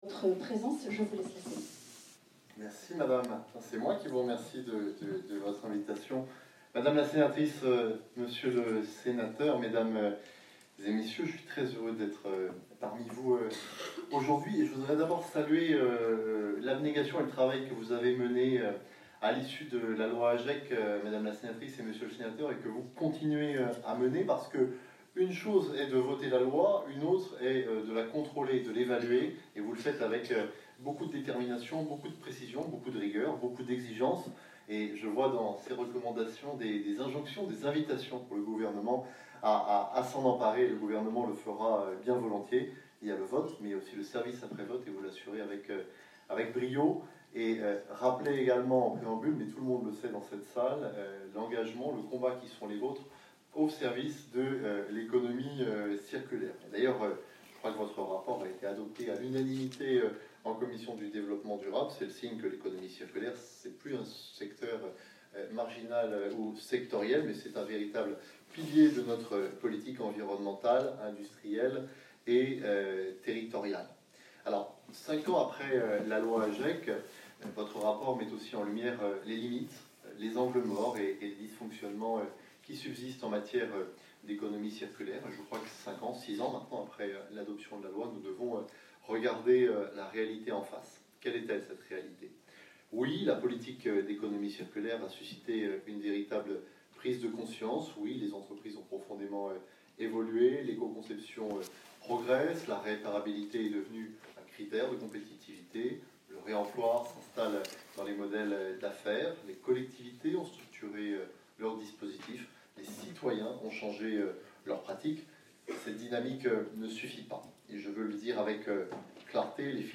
L’allocution de Mathieu Lefèvre le 13 février 2026
Le ministre délégué à la Transition écologique Mathieu Lefèvre a ouvert le colloque sur les filières de responsabilité élargie des producteurs (REP) qui se tenait le 13 février dernier au Sénat, à l’initiative de la sénatrice Marta de Cidrac et du sénateur Jacques Fernique.